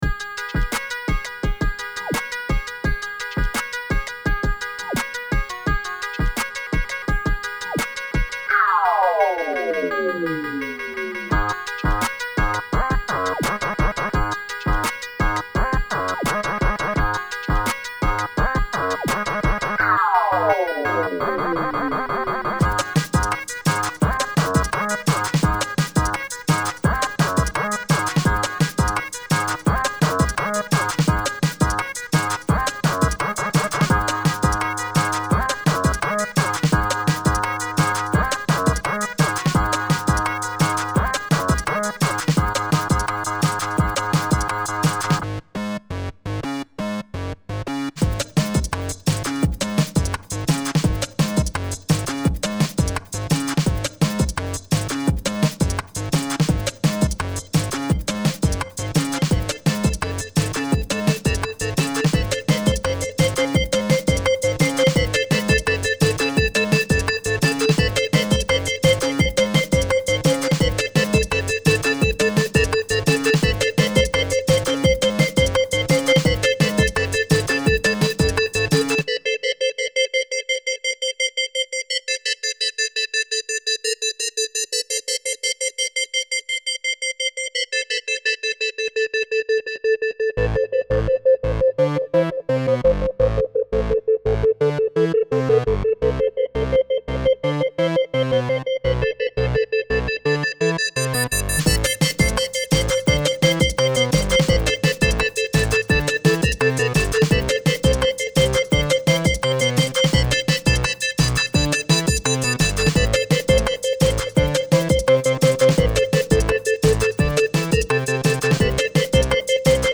Трек на Korg Electribe 2 + Octa
Вся секвенция с Окты, с электрайба только синты, ну и драмы с окты... Опять получилась 8-ми битка, но достаточно бодрая.